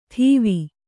♪ ṭhīvi